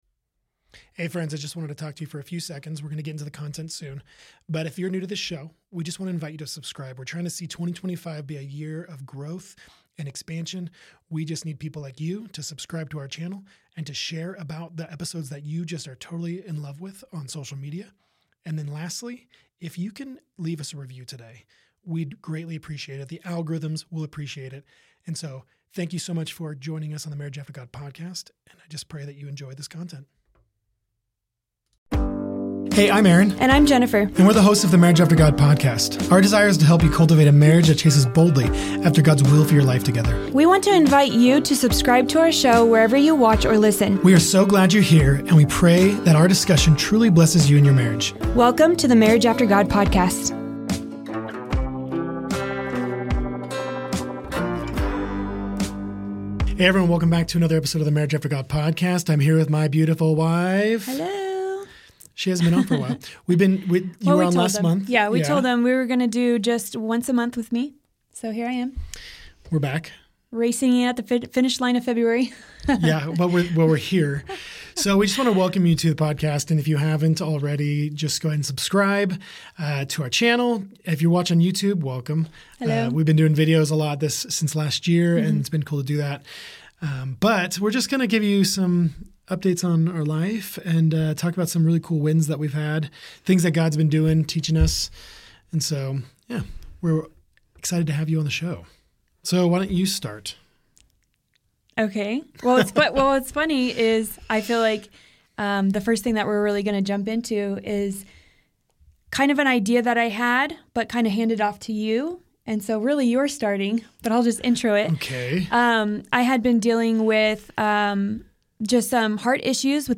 In this heartfelt conversation, we explore: